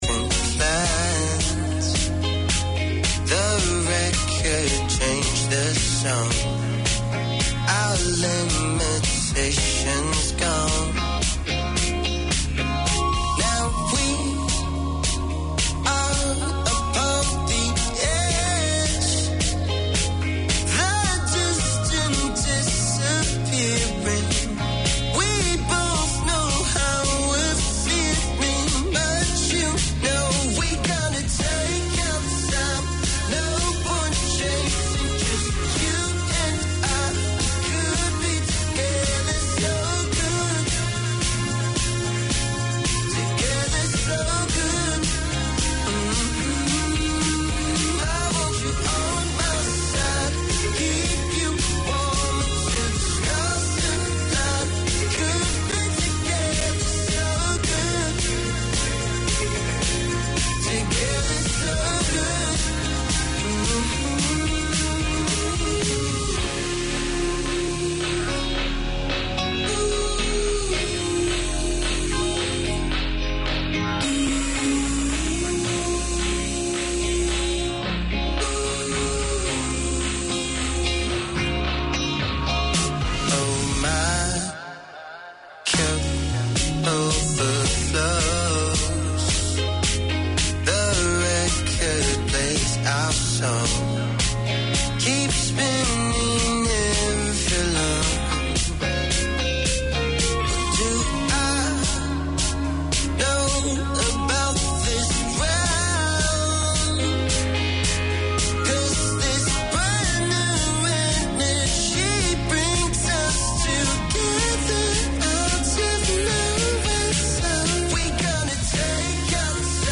From Artificial Intelligence to Zoology, working scientists are bringing science to a street near you. This show talks about science topics and their relevance to our everyday lives in a language that is understandable to the person on the street. Fascinating discussions are delivered along with ‘hot-off-the-press’ science news and a curious selection of the favourite music of scientists.